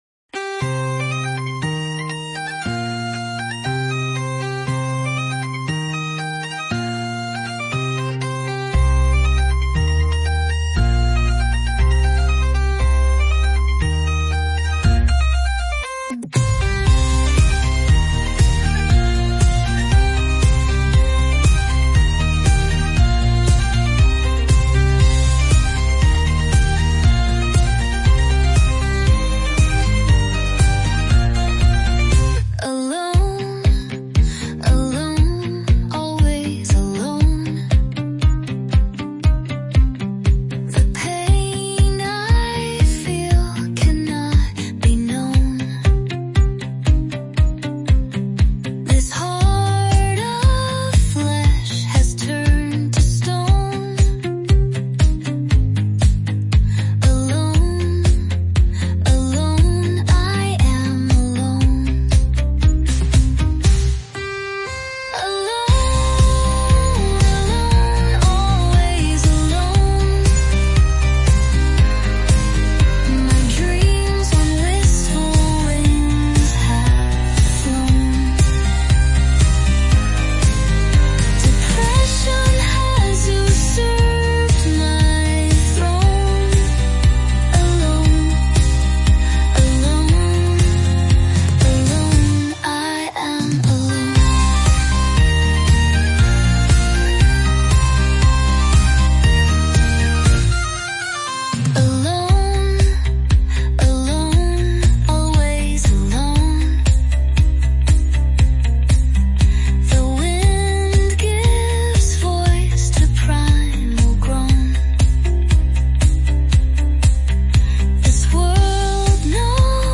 very nice lyrics and music! Enjoyable!
I love the background music and the pretty voice singing.